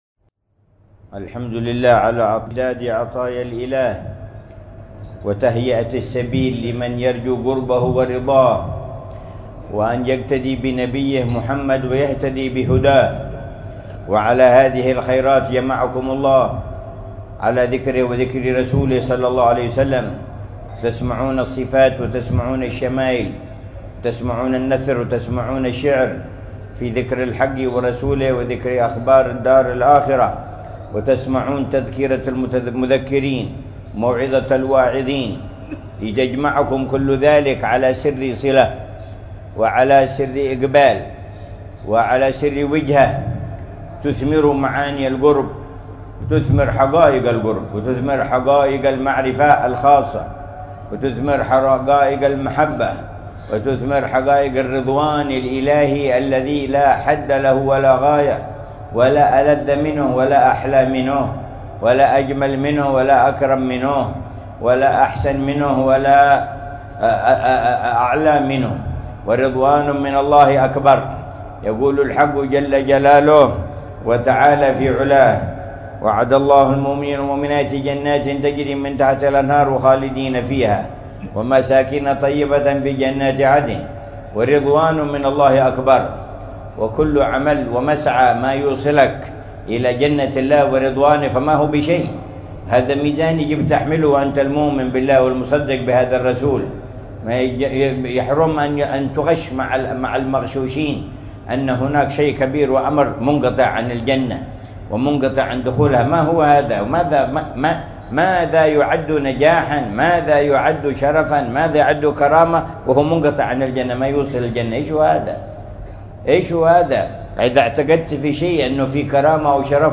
مذاكرة العلامة الحبيب عمر بن محمد بن حفيظ في مسجد الإمام حسن بن عبد الرحمن السقاف بحارة الخليف، مدينة تريم، ليلة السبت 25 ربيع الأول 1446هـ بعنوان: